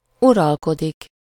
Ääntäminen
IPA : /ruːl/